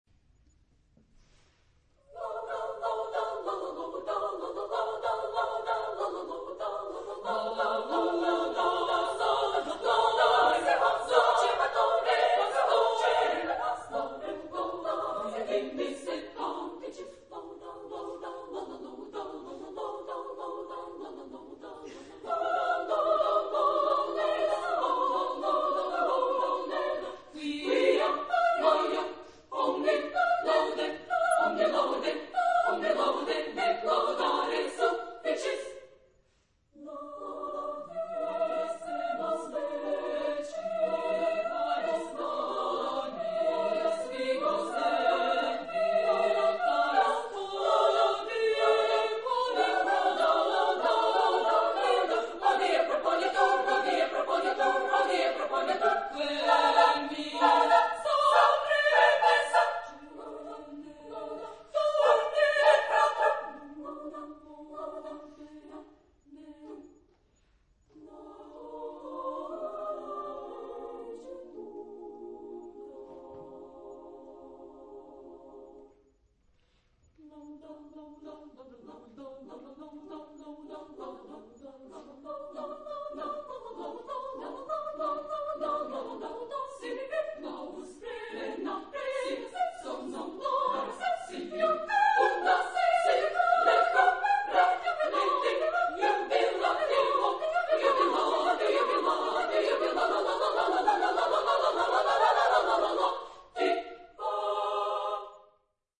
Época : Siglo 20
Género/Estilo/Forma: Sagrado
Carácter de la pieza : alegre
Tipo de formación coral: SSAA  (4 voces Coro femenino )